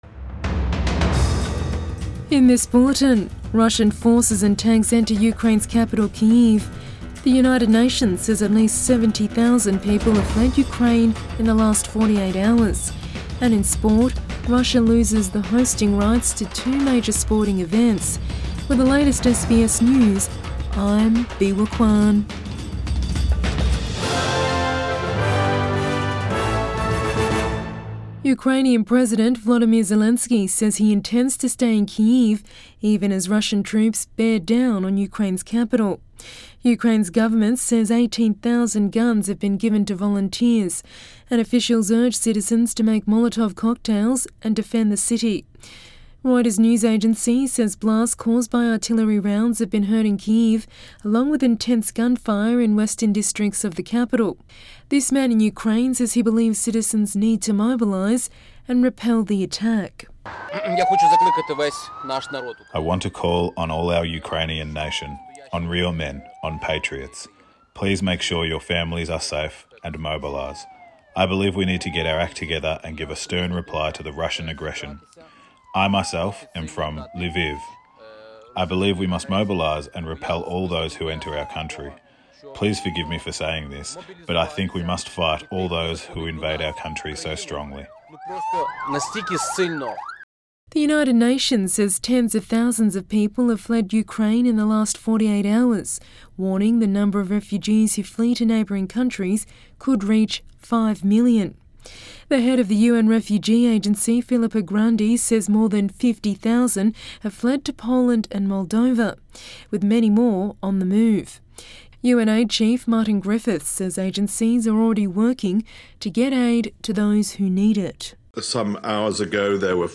AM bulletin 26 February 2022